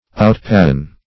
Outpassion \Out*pas"sion\, v. t.